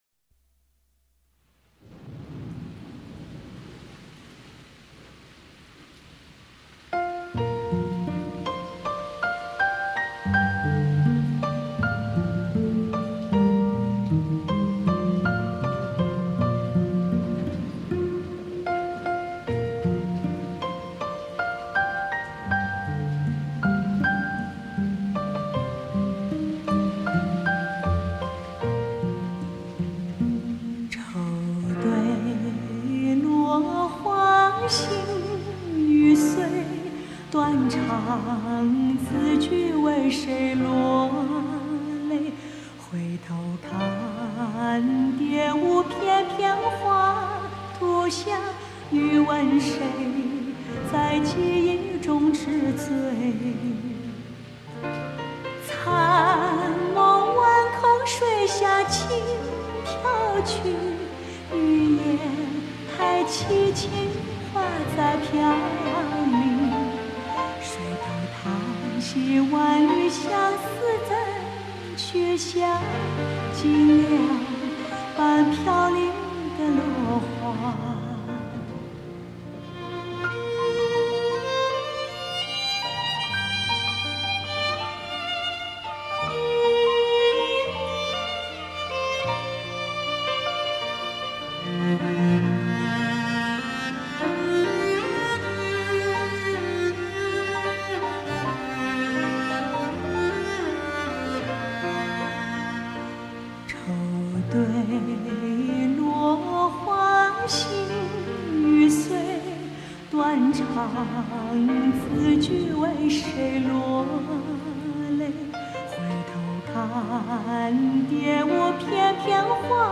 她的歌声最没有现代气息，很容易带你进入那个遥远的年代！